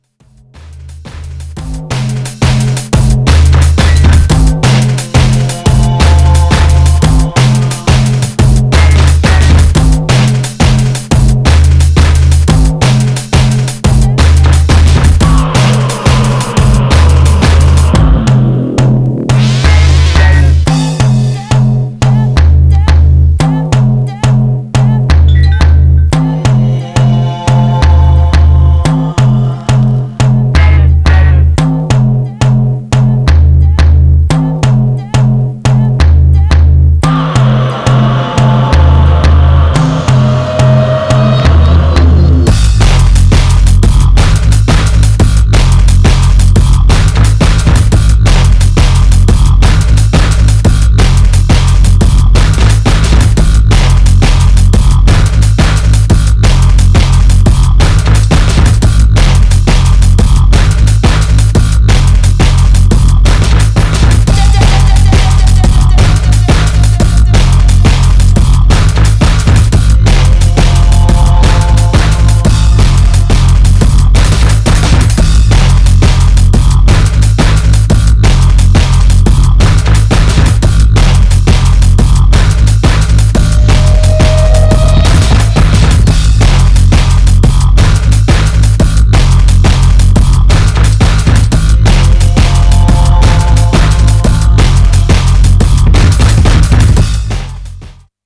Styl: Drum'n'bass, Jungle/Ragga Jungle
Instrumental